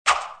Tonos EFECTO DE SONIDO DE AMBIENTE de WISH